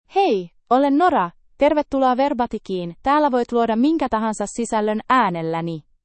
Nora — Female Finnish AI voice
Nora is a female AI voice for Finnish (Finland).
Voice sample
Listen to Nora's female Finnish voice.
Nora delivers clear pronunciation with authentic Finland Finnish intonation, making your content sound professionally produced.